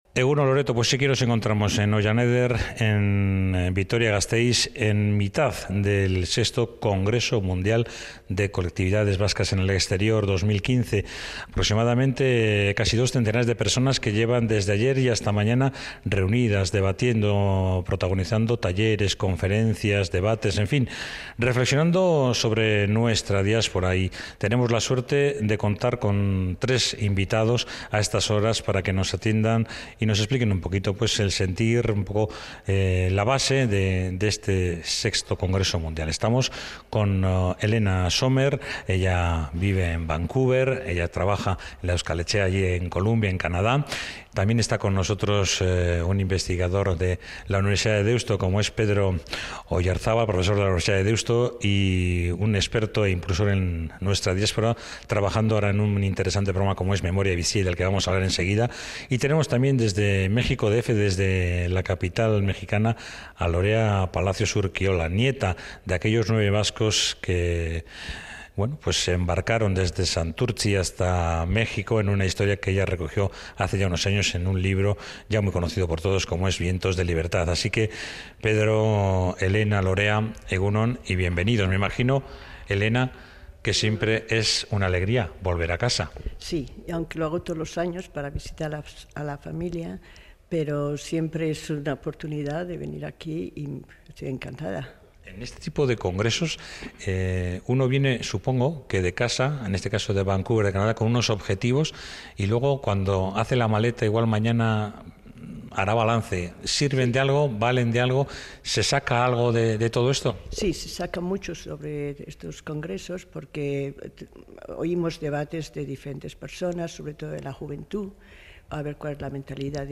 Desde Vitoria-Gasteiz, en Ohianeder-Euskeraren Etxea.
La unidad móvil visita Ohianeder-Euskeraren Etxea en Vitoria-Gasteiz para asistir a la segunda jornada del VI Congreso Mundial de Colectividades Vascas en el Exterior 2.015.